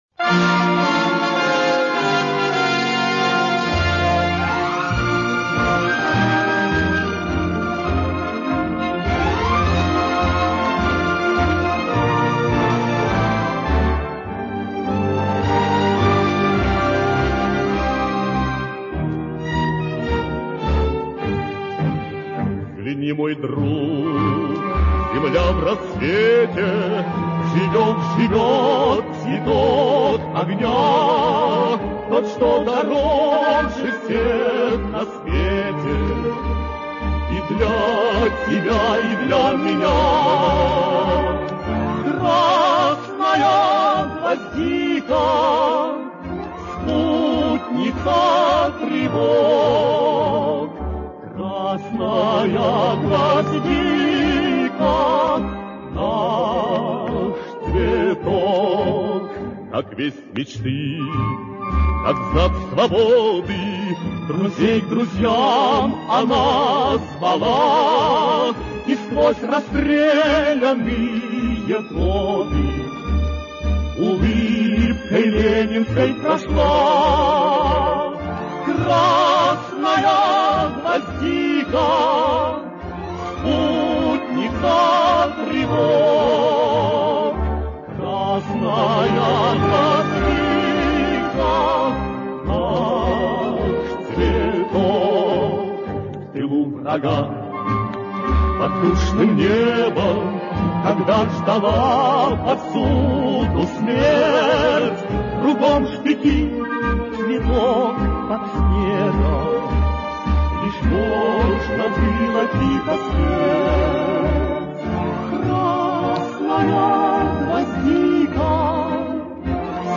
Песня 70-х годов о символе Революции в ином исполнении.